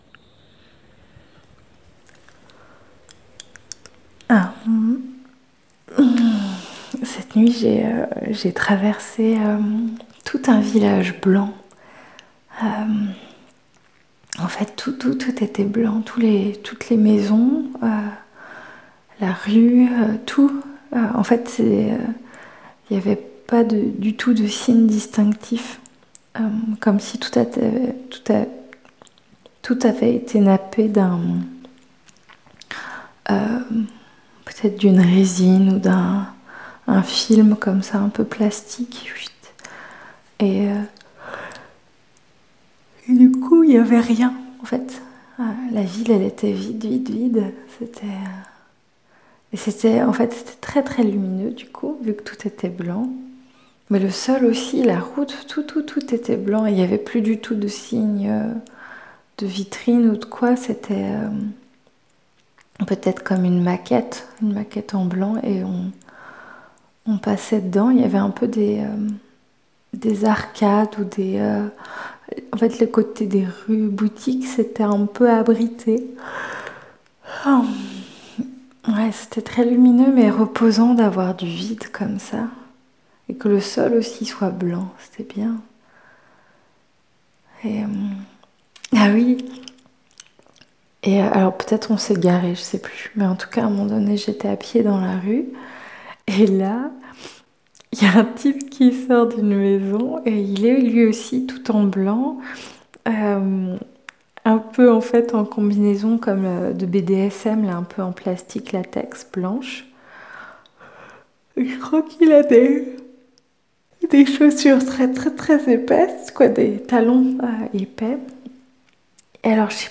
Au réveil le matin, la voix encore embrumée de la nuit des rêveurs et des rêveuses ont essayé de raconter leurs rêves.